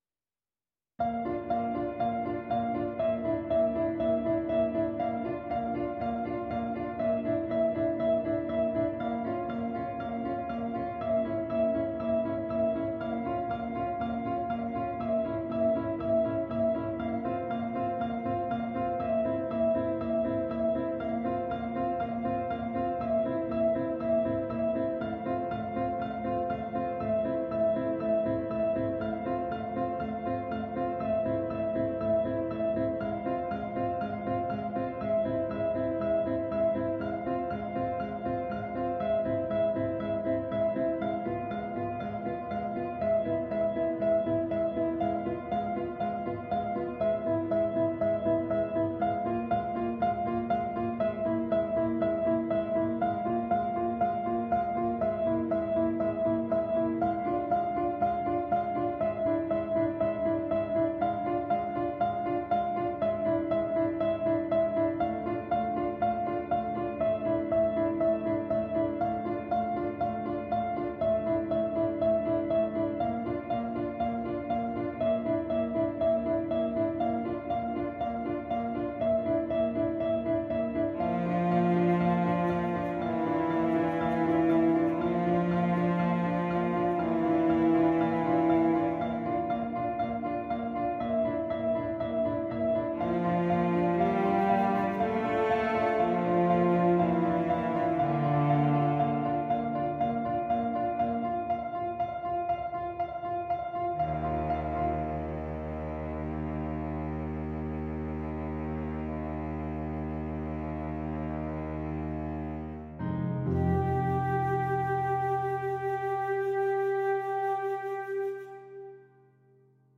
but I’ve also made my own MIDI recording so you can hear it